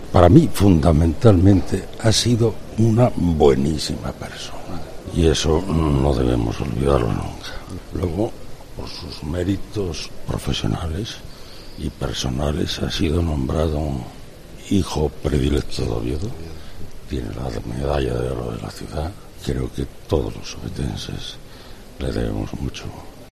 Gabino de Lorenzo expresa su cariño a Gabino Díaz Merchán
A las puertas de la Catedral, De Lorenzo se detenía unos minutos para atender a la Cadena COPE: "para mí fundamentalmente ha sido una buenísima persona, y eso no lo debemos de olvidar nunca".